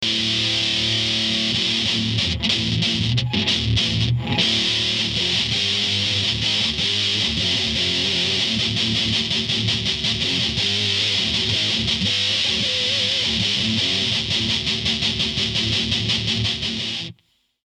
ELECTRO-HARMONIX / Metal Muffは、他に類をみないほどの強烈な歪を生み出すメタル・ディストーション。